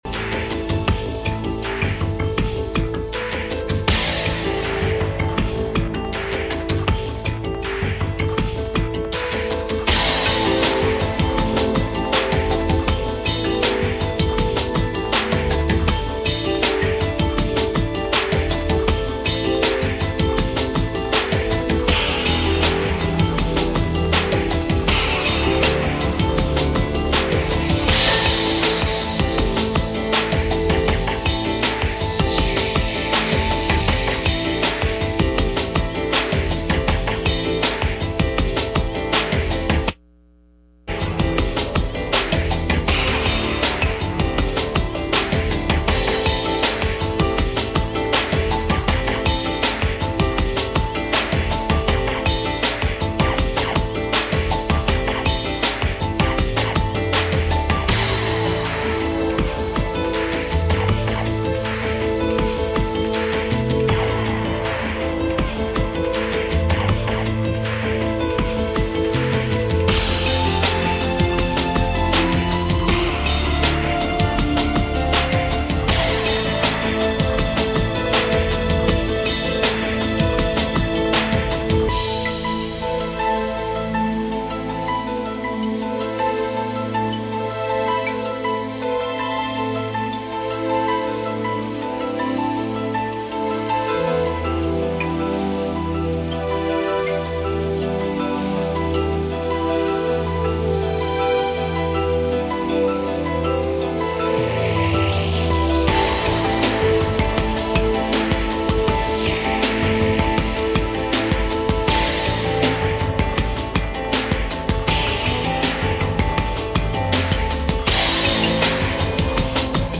Hebrews 12:1-2 Service Type: Midweek Meeting « Go After God